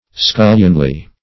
Scullionly \Scul"lion*ly\